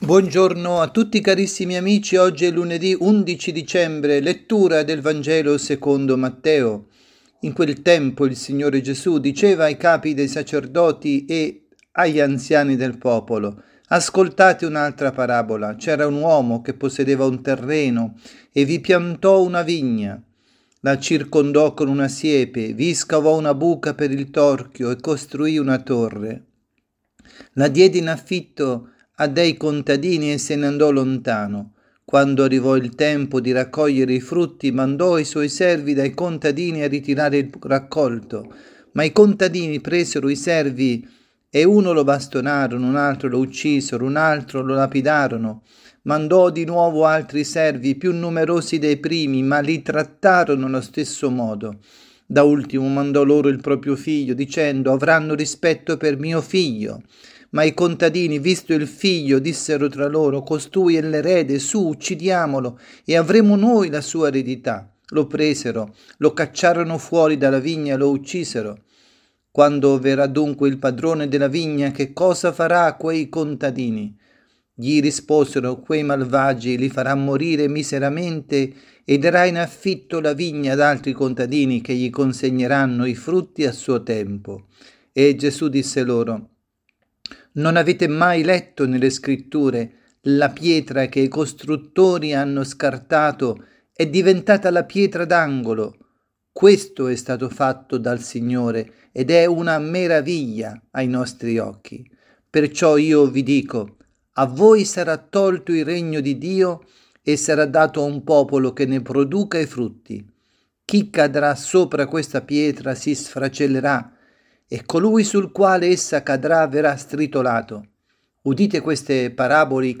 Avvento, avvisi, Omelie